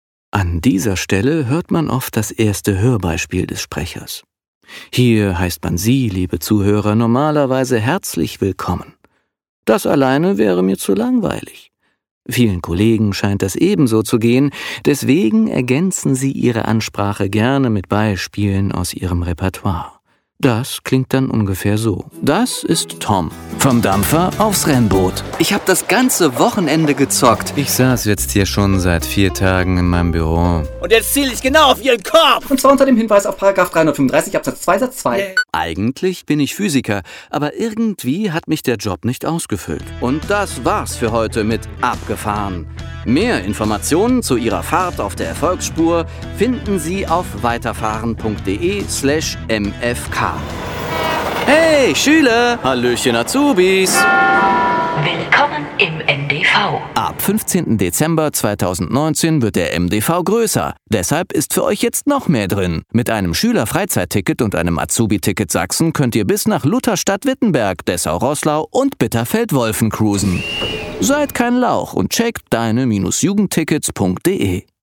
Und genau diese Chamäleonhaftigkeit des Seins, finden wir auch in seinem Voice acting: Sei es in der Rolle als junger Erwachsener oder als frecher Charakter im Trickfilm. Als Voice-Over flippig oder leicht ironisch im Youtube-Stil.